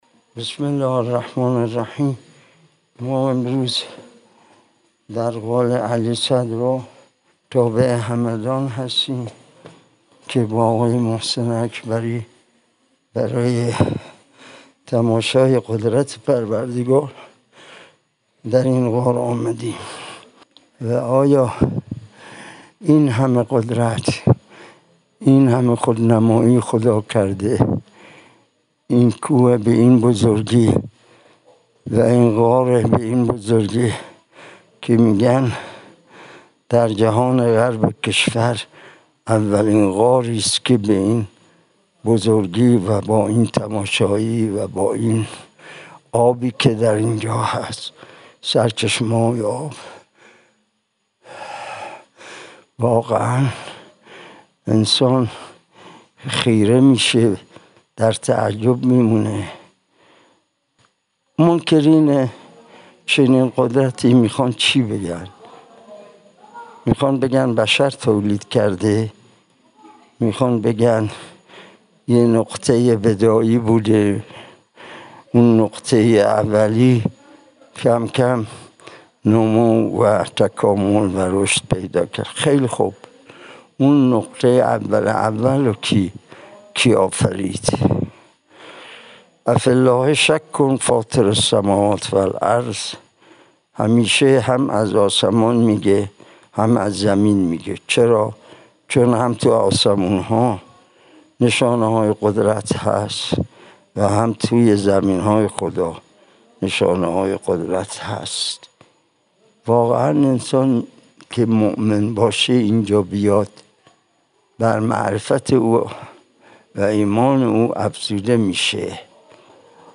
مکان: همدان- غار علیصدر